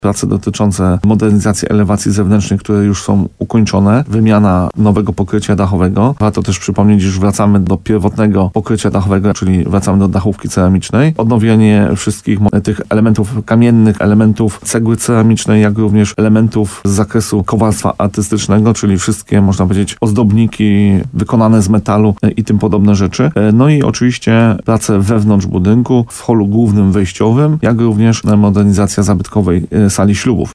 – Trwają teraz dwa z trzech zaplanowanych etapów – mówił w programie Słowo za Słowo w radiu RDN Nowy Sącz burmistrz Grybowa Paweł Fyda.